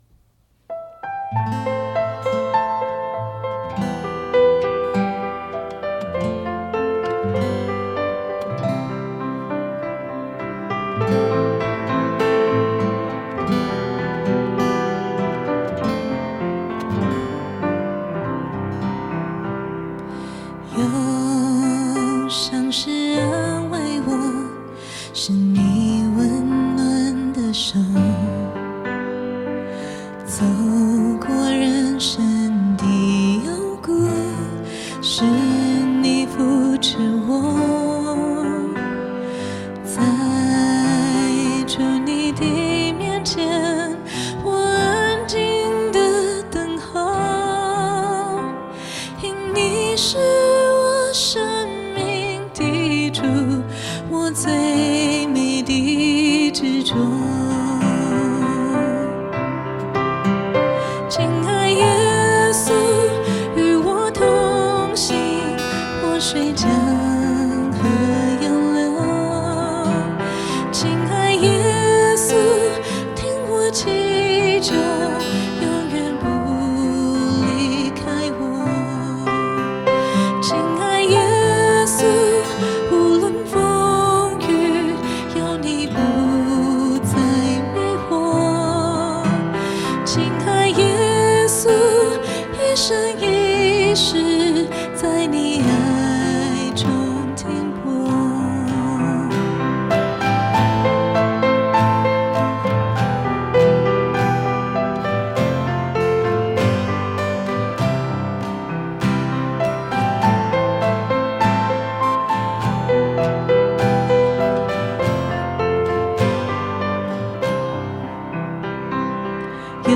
赞美诗